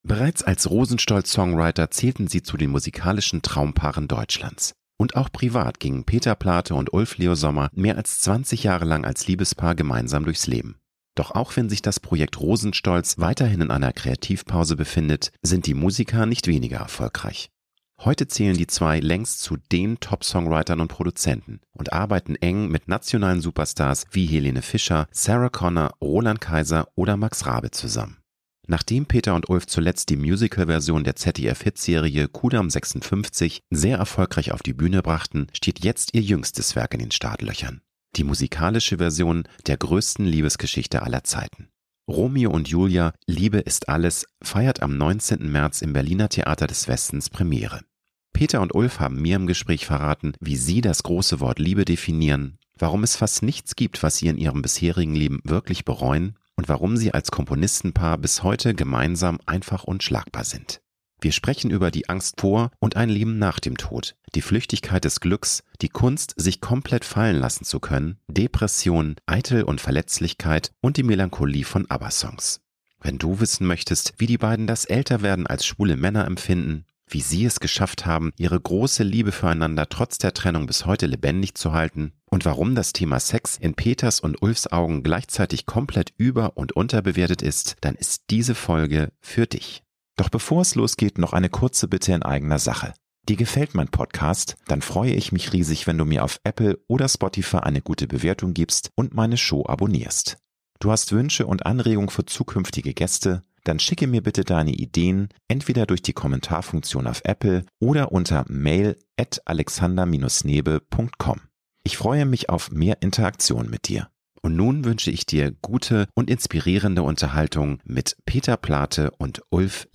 Peter und Ulf haben mir im Gespräch verraten, wie sie das große Wort Liebe definieren, warum es fast nichts gibt, was sie in ihrem bisherigen Leben wirklich bereuen und warum sie als Komponisten-Paar bis heute gemeinsam einfach unschlagbar sind. Wir sprechen über die Angst vor und ein Leben nach dem Tod, die Flüchtigkeit des Glücks, die Kunst, sich komplett fallen lassen zu können, Depressionen, Eitel- und Verletzlichkeit und die Melancholie von ABBA-Songs.